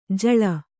♪ jaḷa